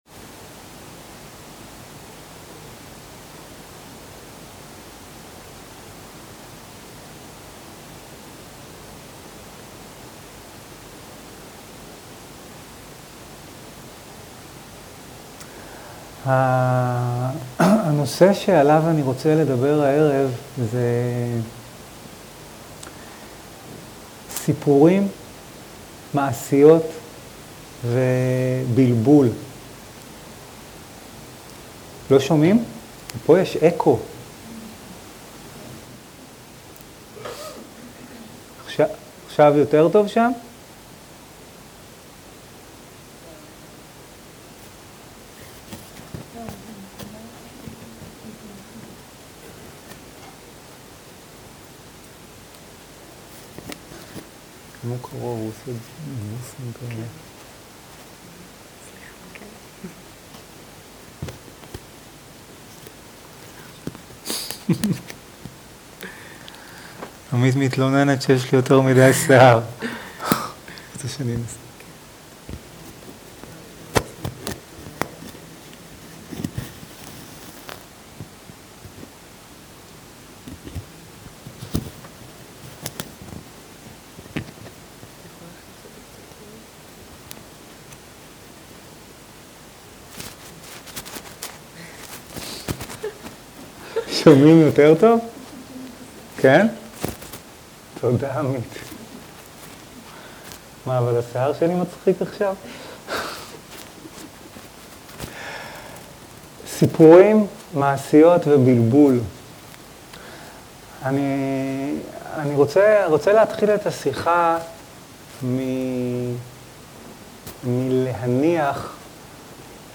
שיחת דהרמה על סיפורים
Dharma type: Dharma Talks שפת ההקלטה